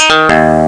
home *** CD-ROM | disk | FTP | other *** search / Amiga Format 71 / af071a.adf / Instruments / CleanGuitar ( .mp3 ) < prev next > Amiga 8-bit Sampled Voice | 1990-02-08 | 14KB | 1 channel | 19,912 sample rate | 0.08 seconds
CleanGuitar.mp3